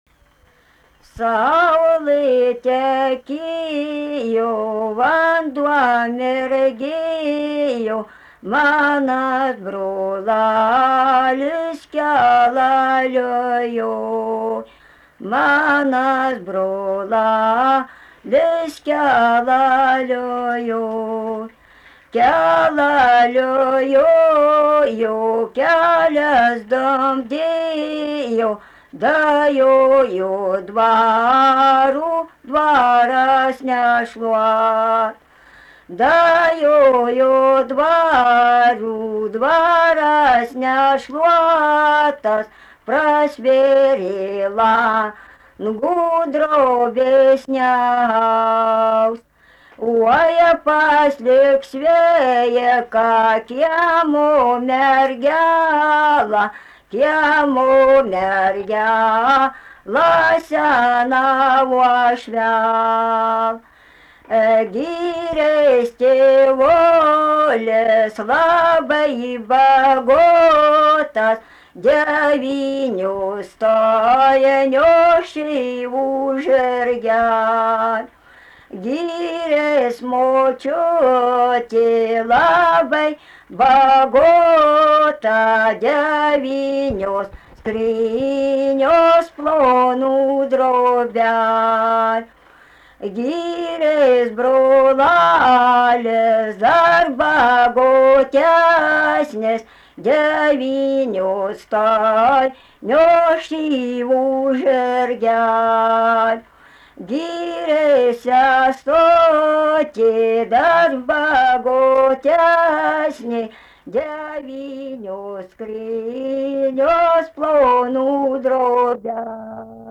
Subject daina
Erdvinė aprėptis Rudnia
Atlikimo pubūdis vokalinis